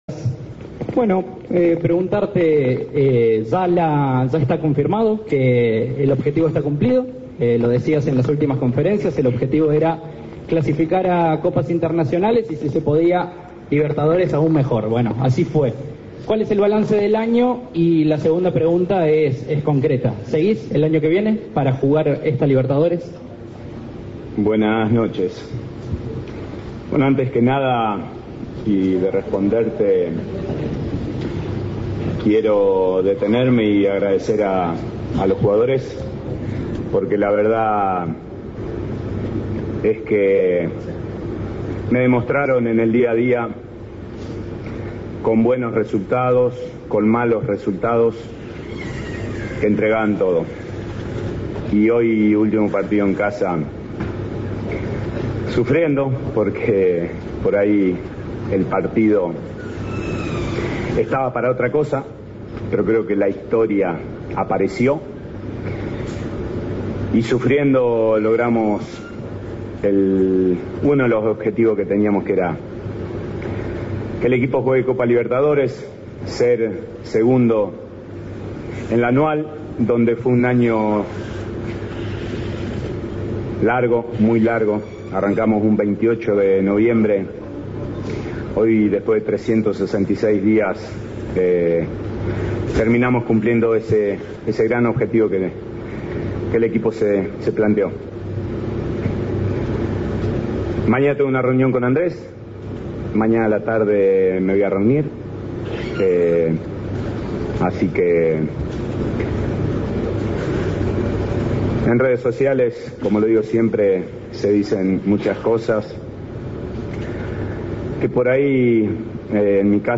El director técnico del Matador habló en conferencia de prensa tras el triunfo 3-2 sobre Independiente y dejó en claro que su continuidad depende de la reunión que mantendrá este lunes con Andrés Fassi.